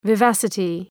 Προφορά
{vı’væsətı}